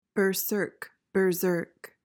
PRONUNCIATION:
(ber-SURK/ZURK)